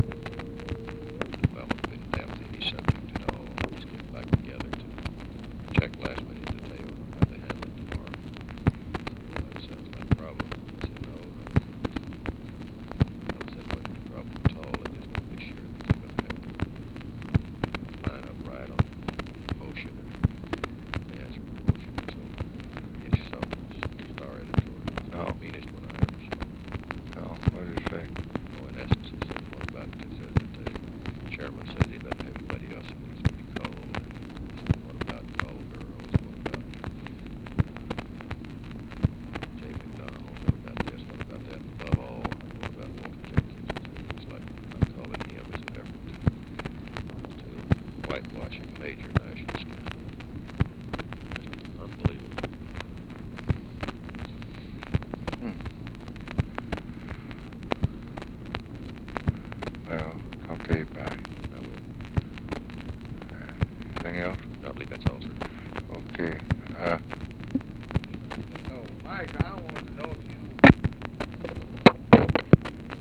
Secret White House Tapes